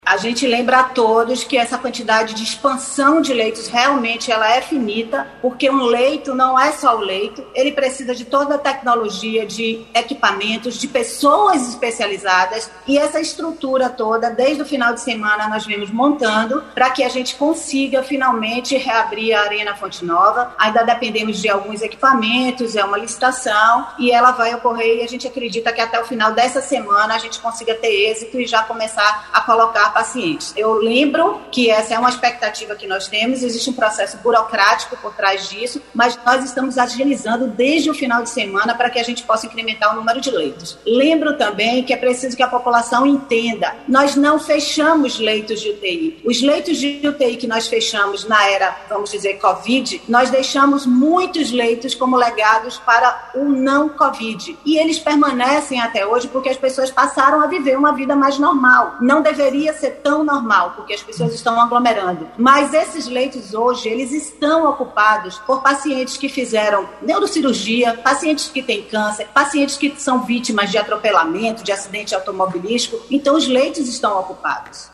“Nós não fechamos leitos de UTI”, explica subsecretária estadual da saúde